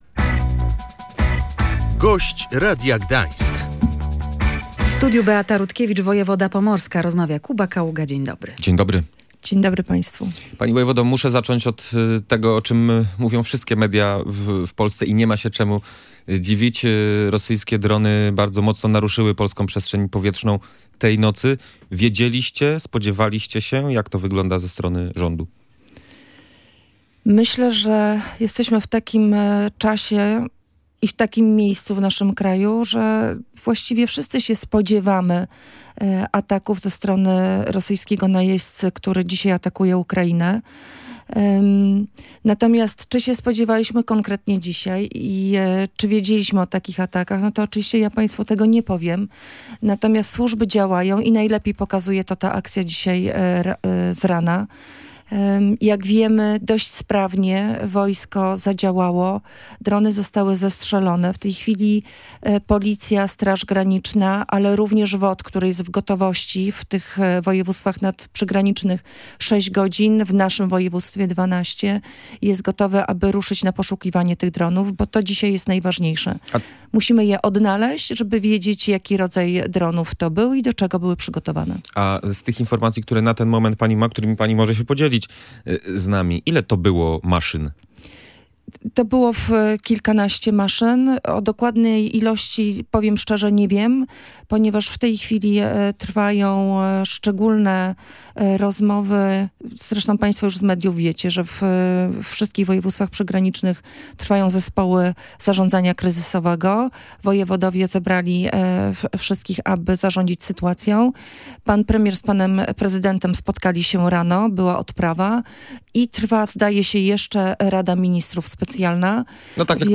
Nie ma powodów do paniki, system ochrony zadziałał – mówiła w Radiu Gdańsk wojewoda pomorska Beata Rutkiewicz. Apelowała jednocześnie, by zdobywać informacje ze sprawdzonych źródeł.